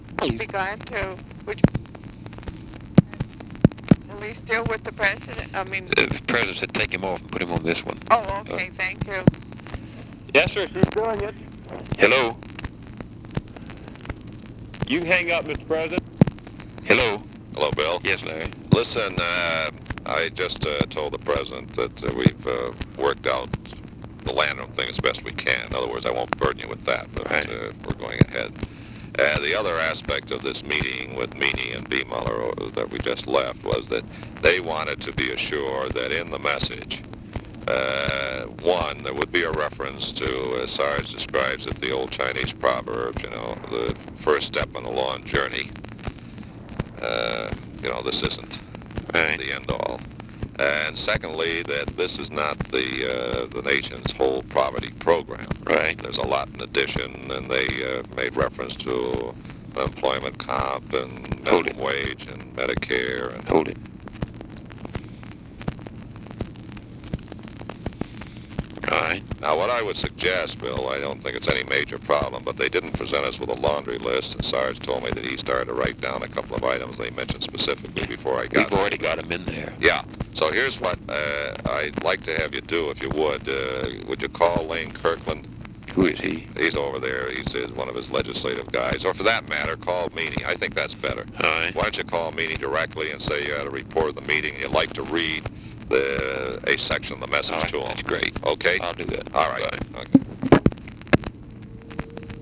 Conversation with LARRY O'BRIEN
Secret White House Tapes